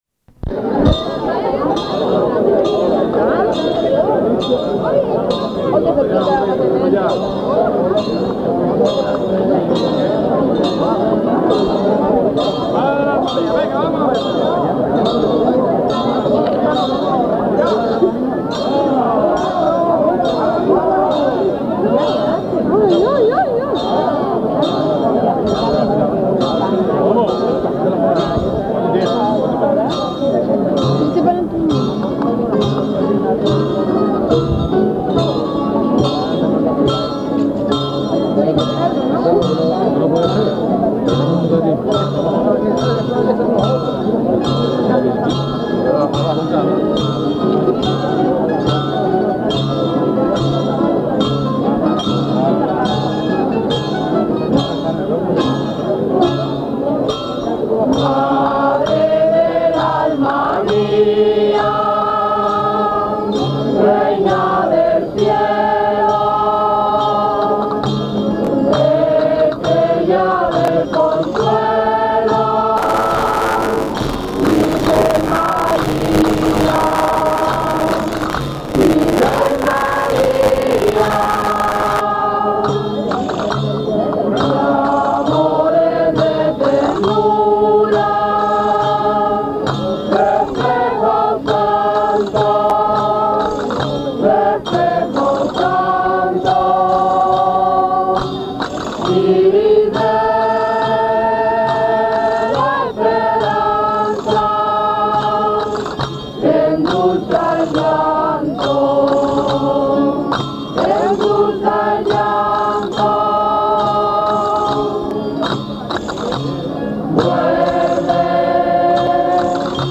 COPLAS DE LOS CAMPANILLEROS DEL VALLE